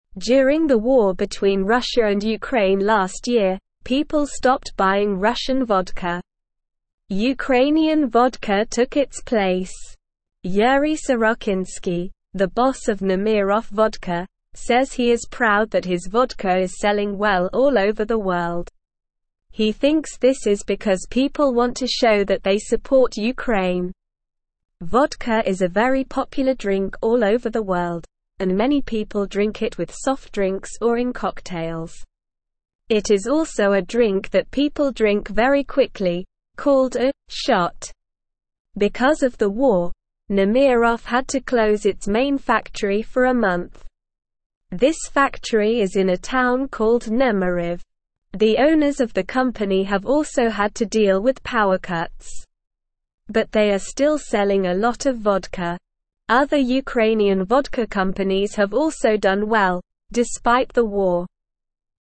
Slow
English-Newsroom-Beginner-SLOW-Reading-Ukrainian-Vodka-Popular-During-War.mp3